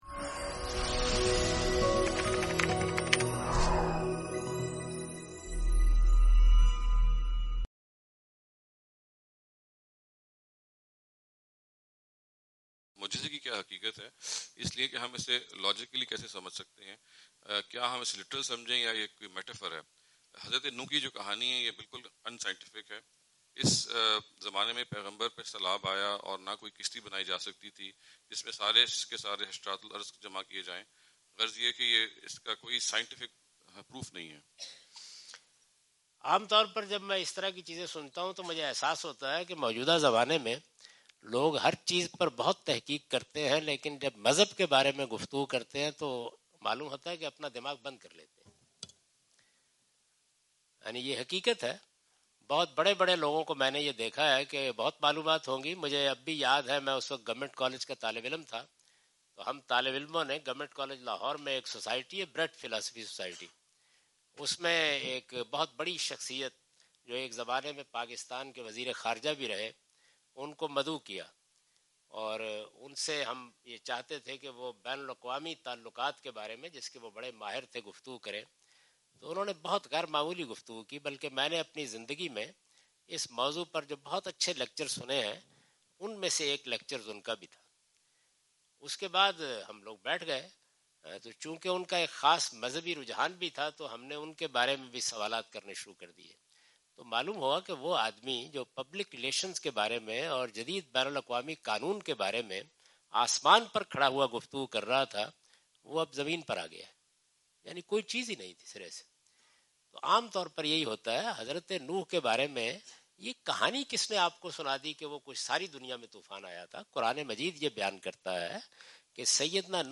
Javed Ahmad Ghamidi answer the question about "Reality of Miracles" during his US visit on June 13, 2015.
جاوید احمد غامدی اپنے دورہ امریکہ 2015 کے دوران سانتا کلارا، کیلیفورنیا میں "معجزات کی حقیقت" سے متعلق ایک سوال کا جواب دے رہے ہیں۔